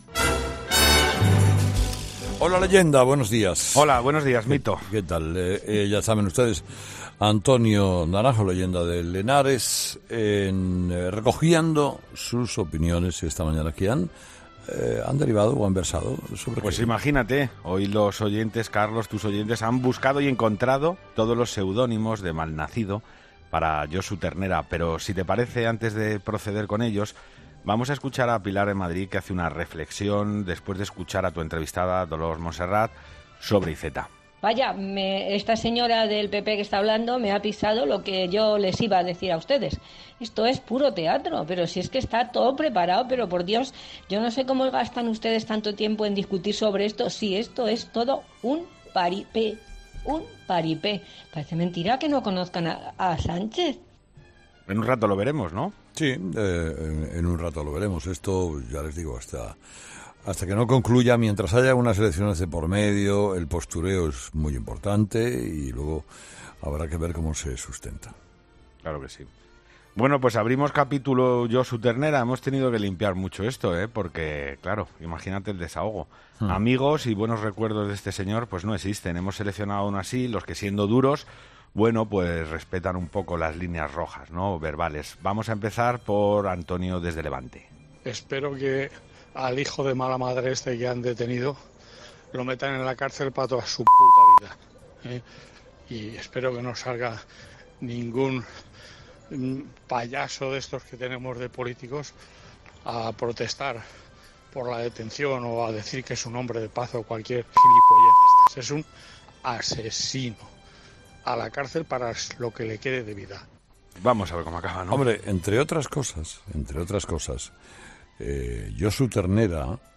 La tertulia de los oyentes